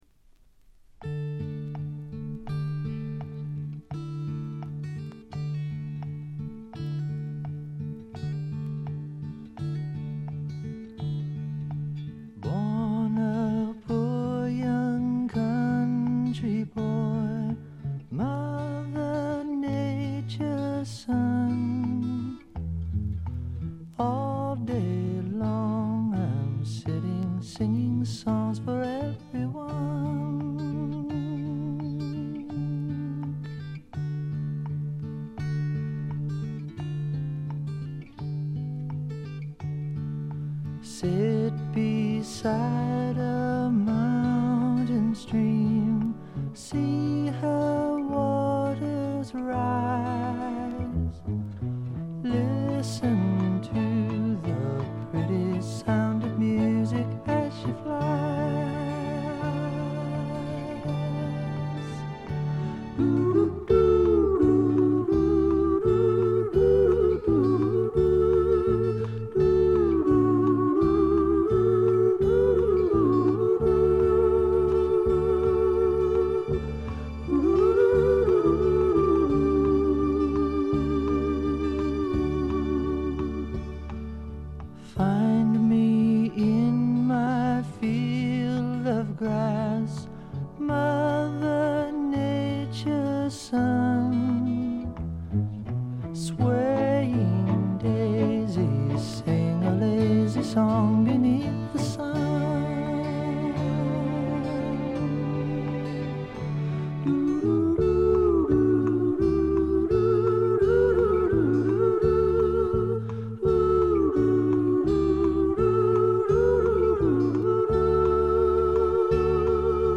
これ以外はわずかなノイズ感のみで良好に鑑賞できると思います。
シンガー・ソングライターの基本盤です。
試聴曲は現品からの取り込み音源です。
Recorded in Hollywood, California.